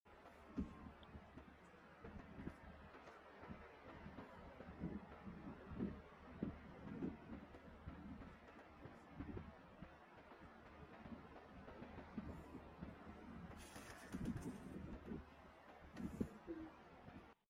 Kitten observation of the outside